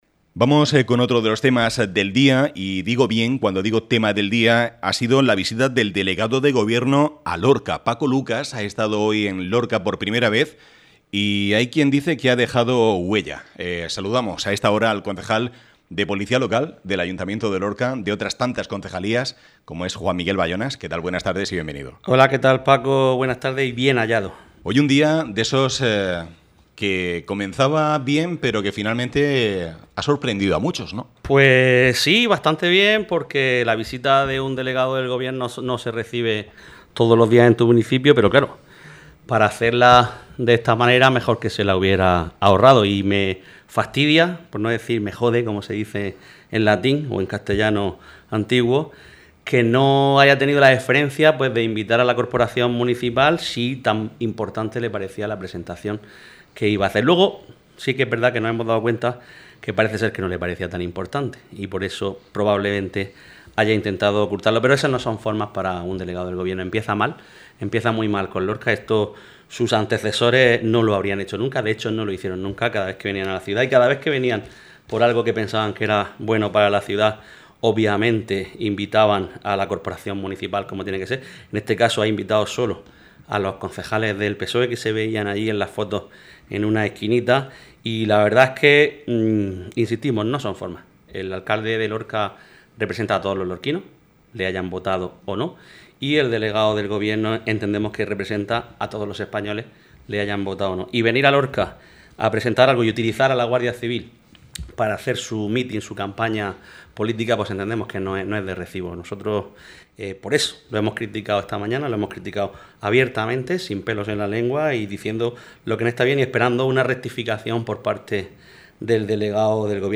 Escucha aquí la entrevista que ha concedido el concejal de Seguridad Ciudadana del Ayuntamiento de Lorca